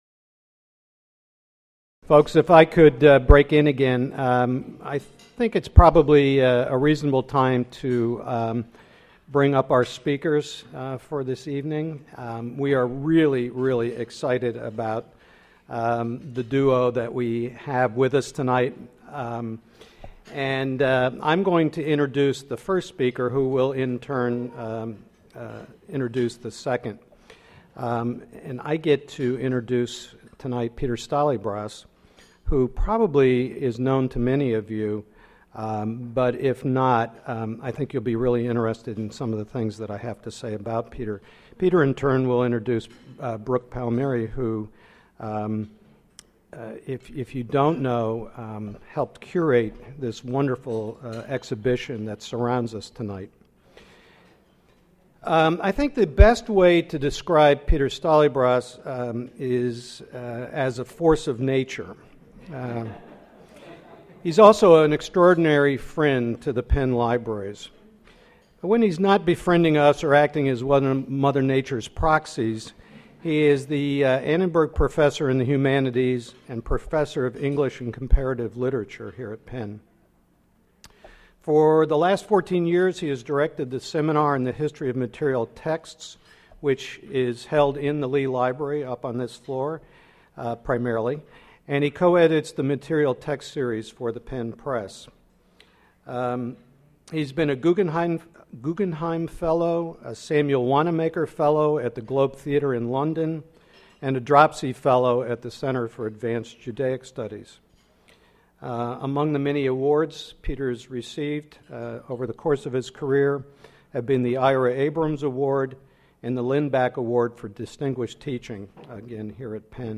Orrery Society Inaugural Presentation